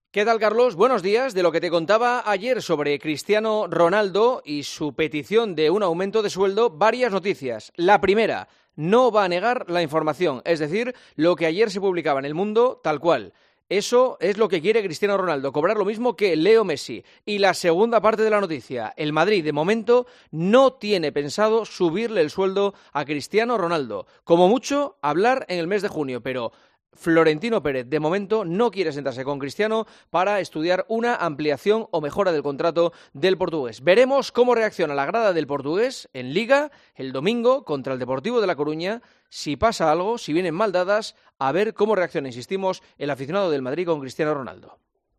El comentario de Juanma Castaño